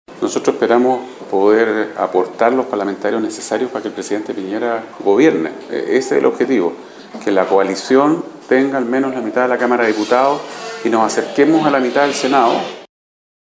Al respecto habló el secretario general de Renovación Nacional, Mario Desbordes.